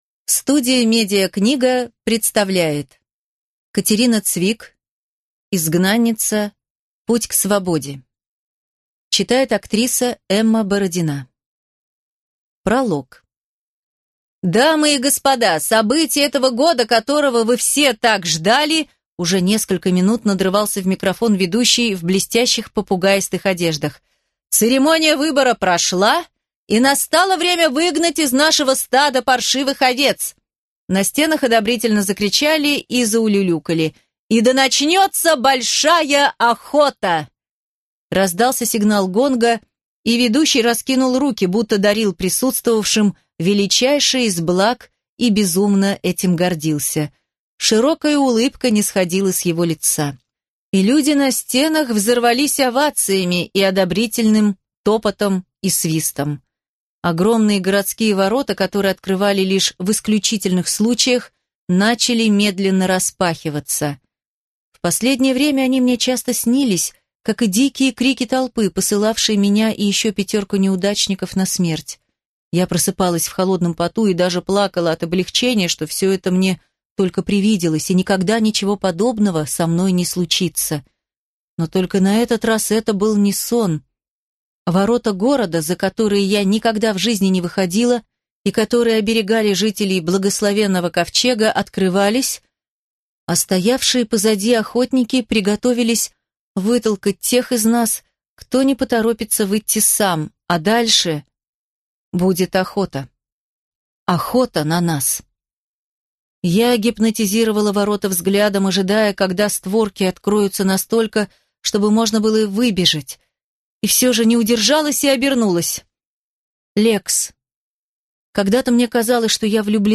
Аудиокнига Изгнанница. Путь к свободе | Библиотека аудиокниг
Прослушать и бесплатно скачать фрагмент аудиокниги